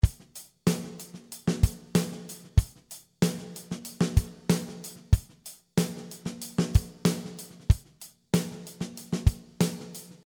Dark and alternative rock style